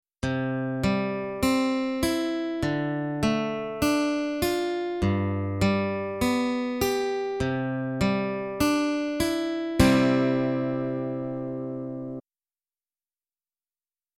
In this example, the chord is played as an arpeggio.  This means that the chord is played one note at a time instead of at the same time.
Arpeggio Finger Picking - Exercise 1
e1_arpeggios.mp3